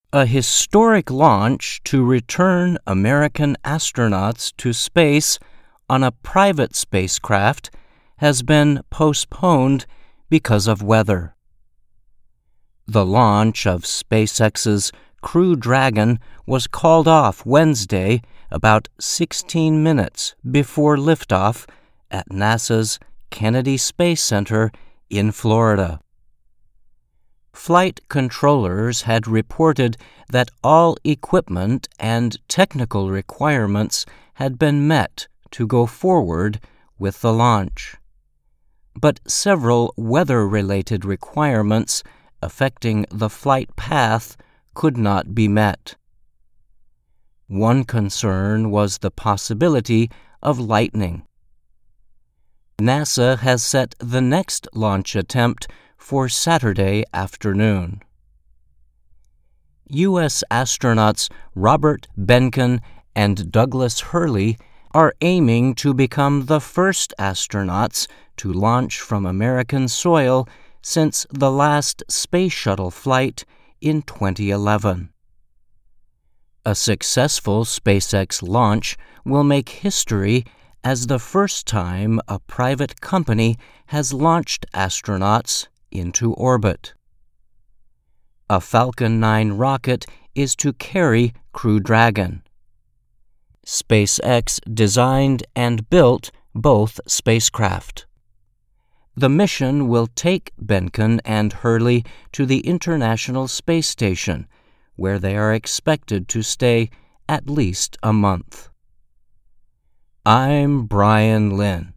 ,慢速英语, 科技报道